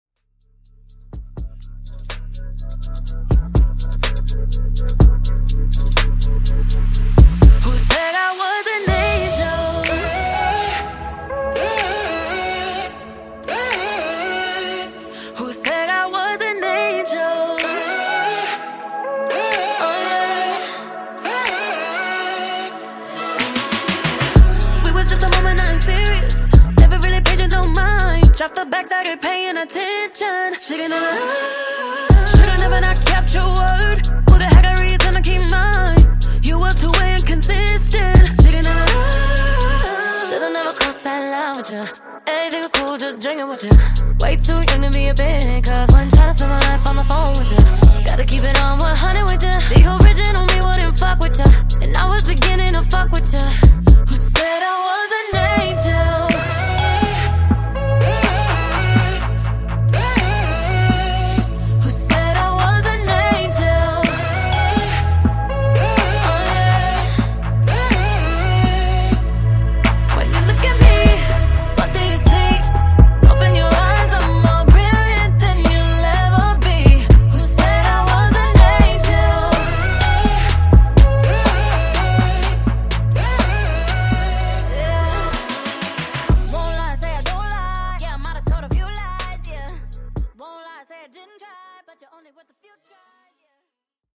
Genre: RE-DRUM
Clean BPM: 90 Time